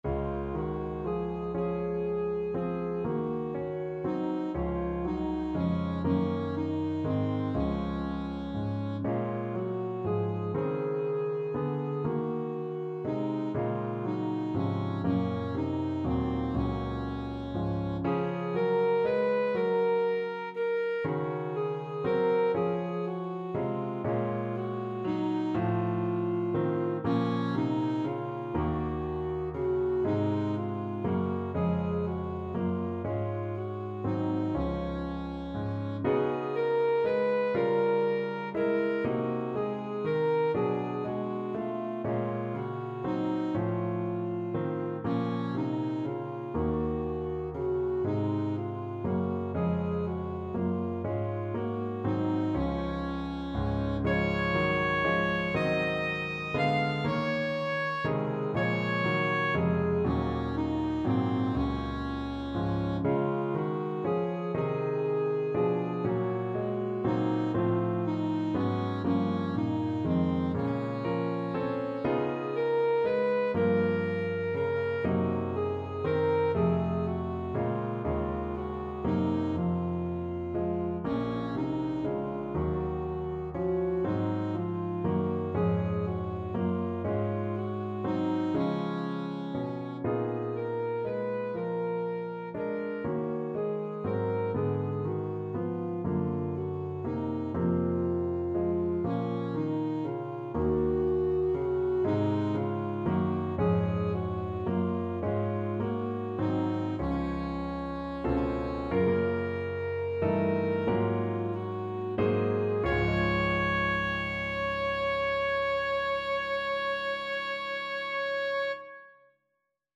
Alto Saxophone
9/4 (View more 9/4 Music)
=120 Andante tranquillo
Classical (View more Classical Saxophone Music)